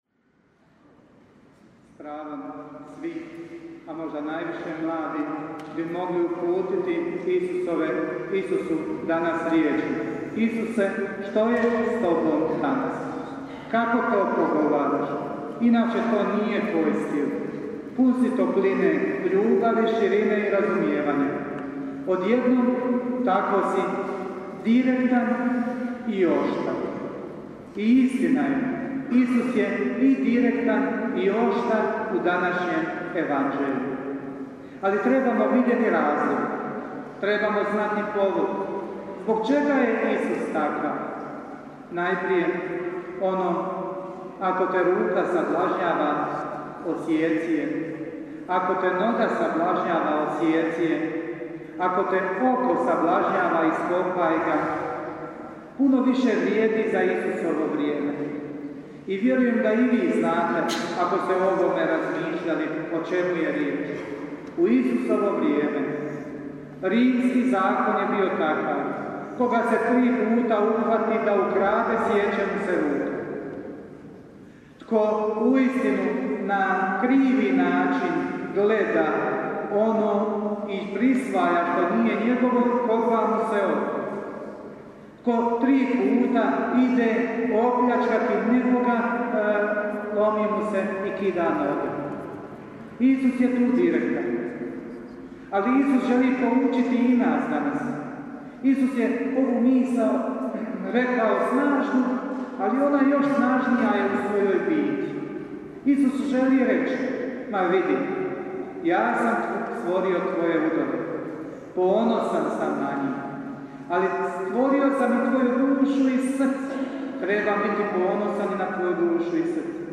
PROPOVJED: